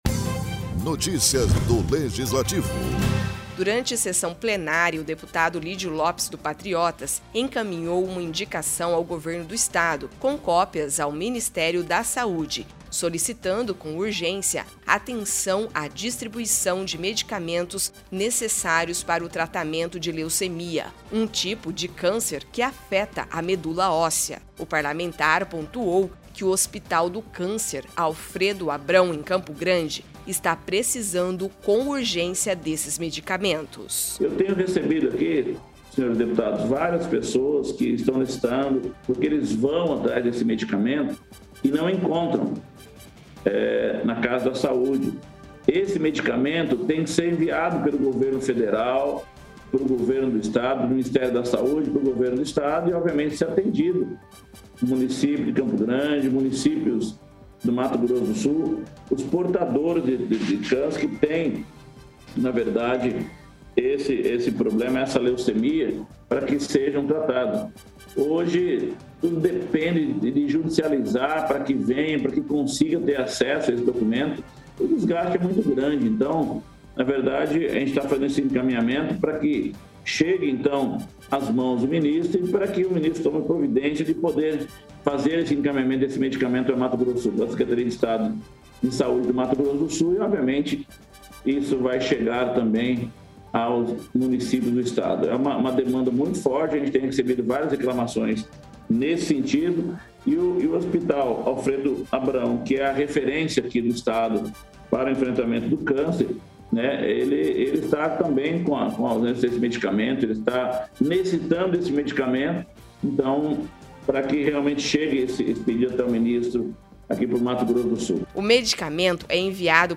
Durante sessão plenária o deputado Lidio Lopes (PATRI) encaminhou uma indicação ao Governo do Estado, com cópias ao Ministério da Saúde, solicitando com urgência atenção à distribuição de medicamentos necessários para o tratamento de leucemia, um tipo de câncer que afeta a medula óssea.